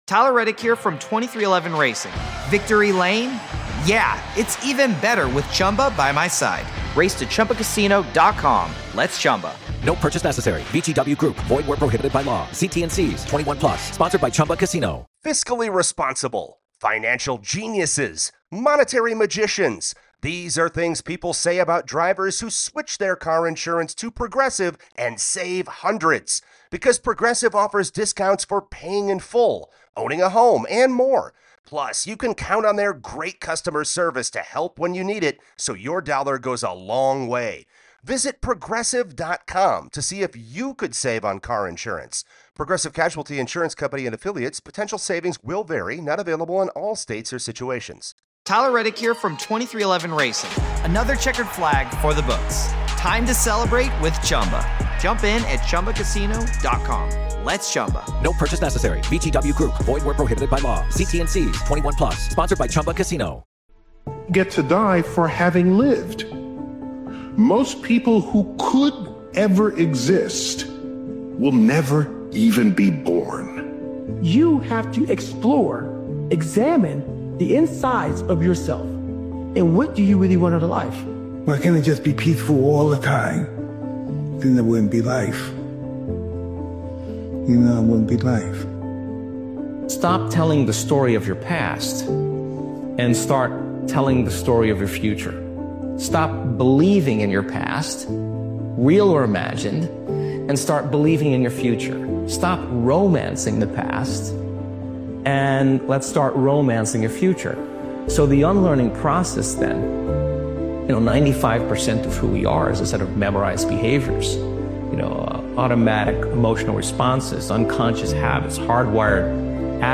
Powerful Motivational Speech is a hard-hitting and accountability-driven motivational speech created and edited by Daily Motivations. This powerful motivational speeches compilation delivers a simple but brutal truth—pain is unavoidable.